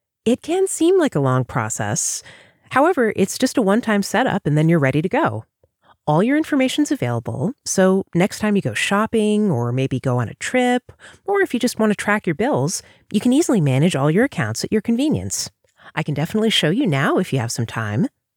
Natural delivery, interesting and pleasant to listen to for hours on end.
Offering narration of all types of online courses, training videos, and eLearning – especially medical, scientific, clinical, and technical!